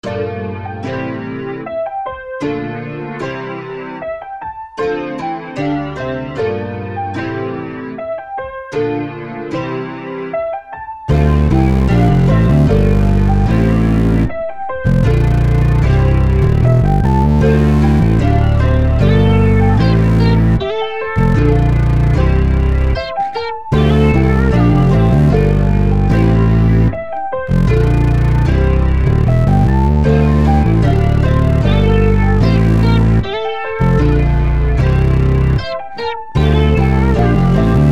Dark/Psychedelic Trap